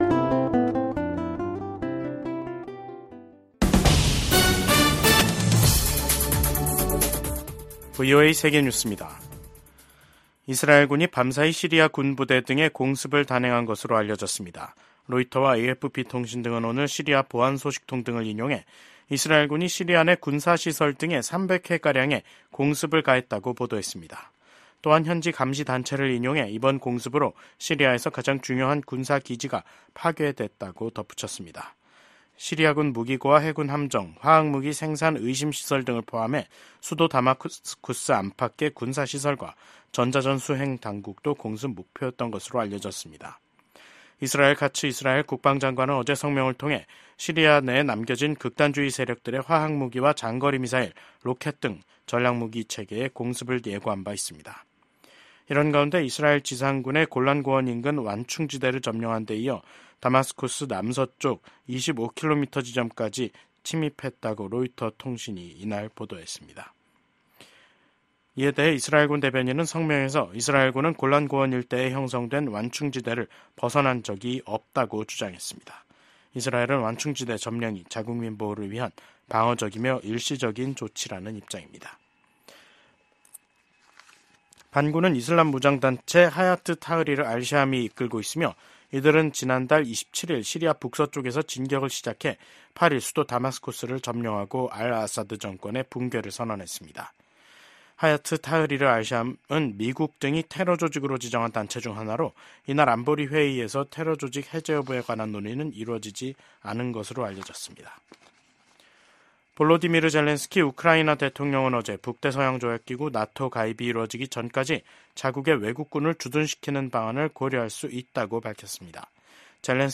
VOA 한국어 간판 뉴스 프로그램 '뉴스 투데이', 2024년 12월 10일 3부 방송입니다. 비상계엄 사태를 수사하는 한국 검찰은 이 사태를 주도한 혐의를 받고 있는 김용현 전 국방부 장관에 대해 구속영장을 청구했습니다. 미국 국무부는 한국의 정치적 혼란 상황이 법치에 따라 해결돼야 한다는 원칙을 재확인했습니다.